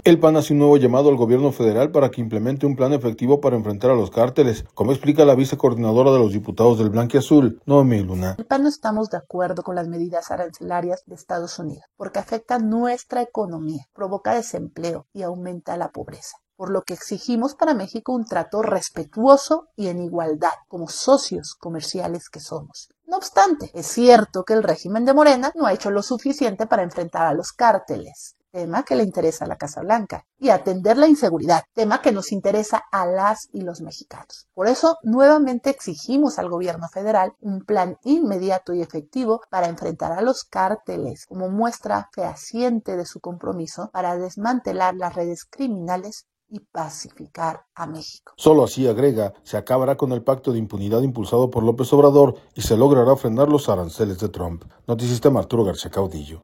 El PAN hace un nuevo llamado al Gobierno Federal para que implemente un plan efectivo para enfrentar a los cárteles, como explica la vice coordinadora de los diputados del blanquiazul, Noemí Luna.